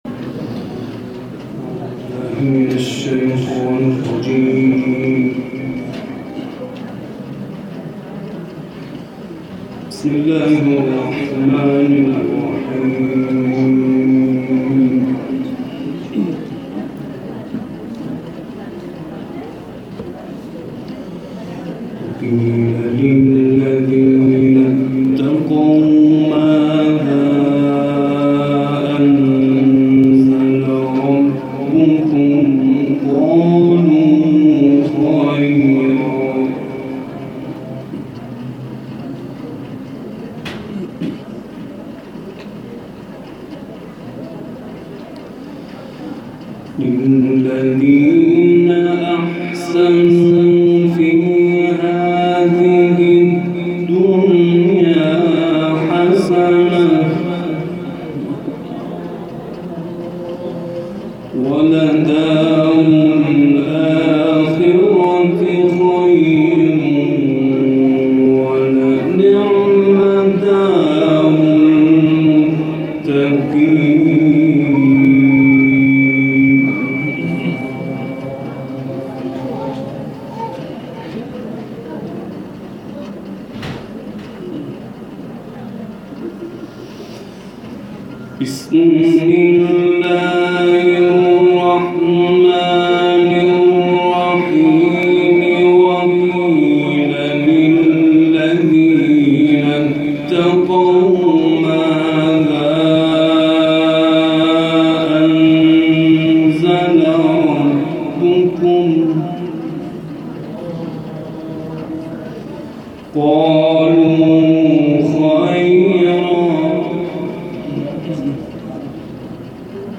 این تلاوت در شب بیست و سوم ماه مبارک رمضان در محفل انس با قرآن در شهر بندرانزلی استان گیلان اجرا شده است.